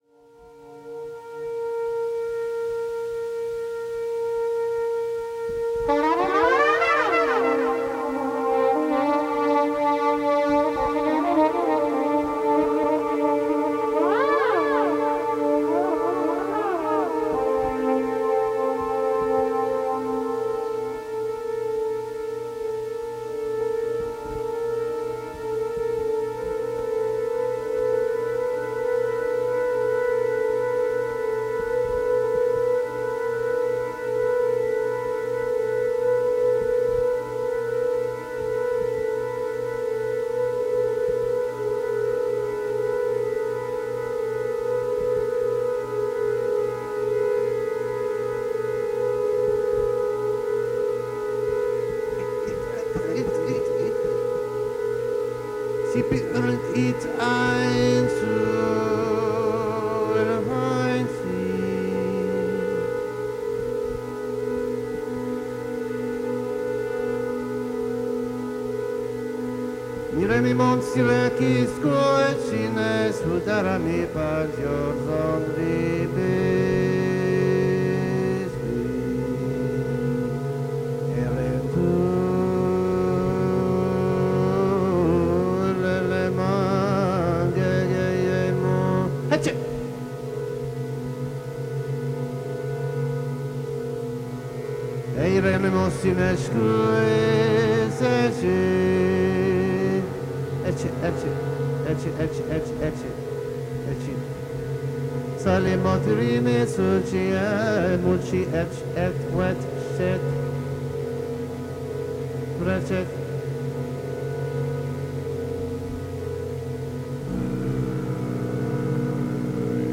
Recorded live at Barbes, Park Slope, Brooklyn.
alto clarinet, fx, vocal
peck horn, percussion, fx
Stereo (722 / Pro Tools)